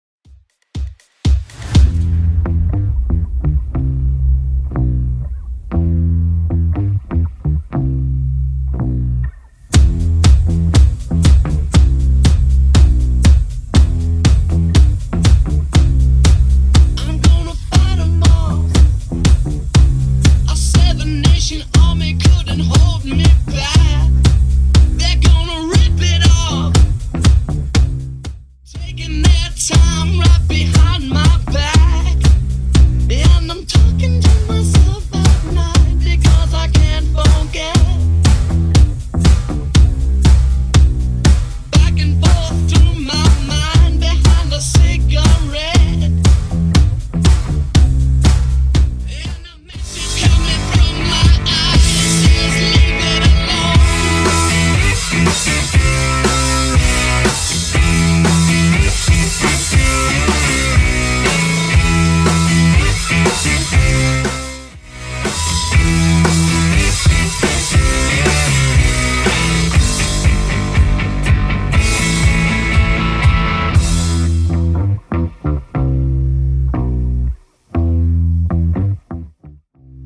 Genre: 80's
BPM: 120